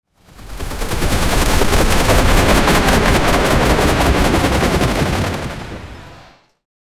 5-sec cinematic sound: helicopter hovering, propellers chopping, giant stone whooshes down, thunderous impact on massive cargo ship, metal bending, containers smashing, ocean splashing, VFX disaster, no humans. 0:07
5-sec-cinematic-sound-hel-5jtj22w7.wav